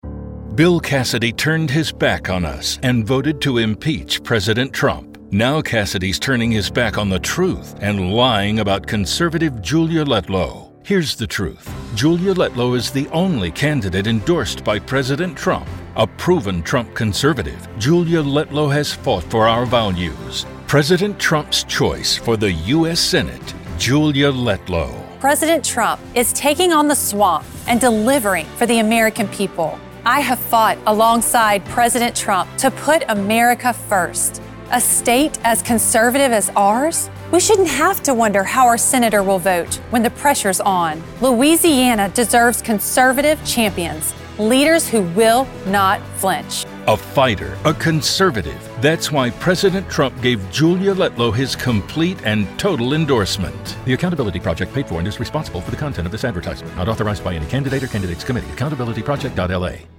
Index of /Audio/Commercials/Accountability Project